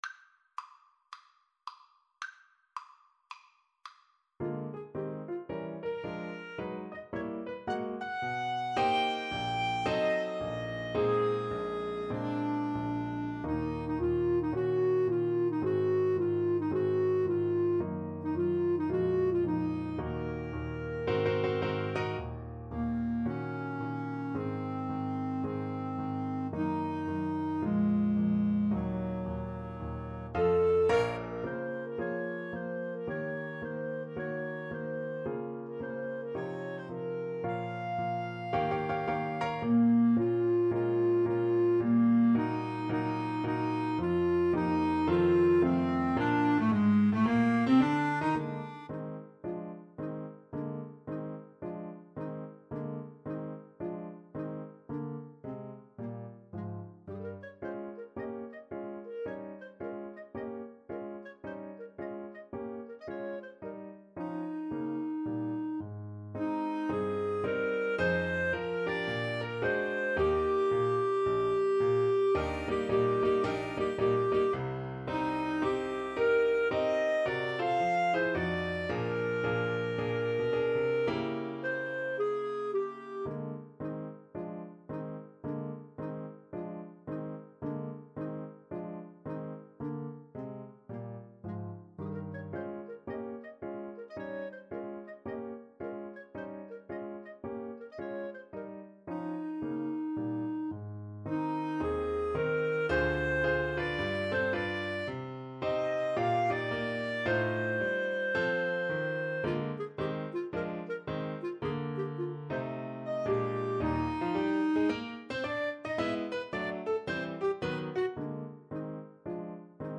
Moderato =110 swung
Classical (View more Classical Clarinet Duet Music)